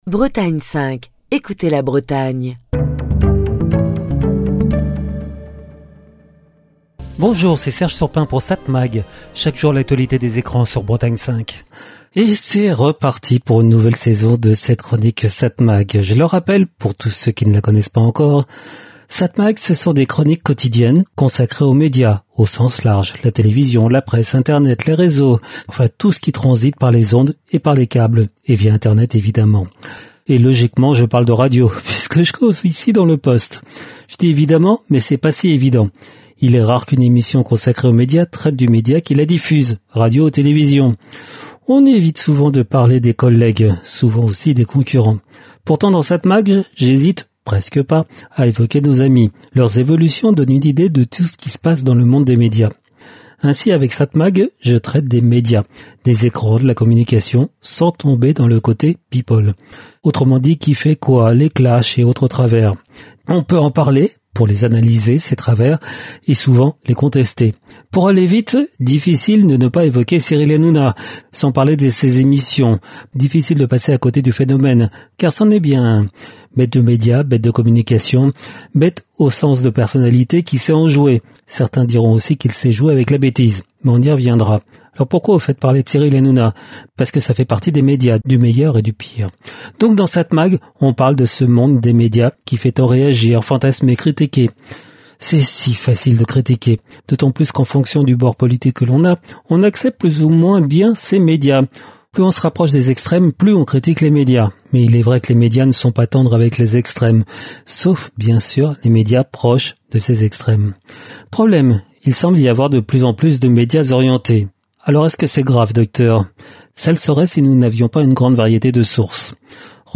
Chronique du 25 août 2025.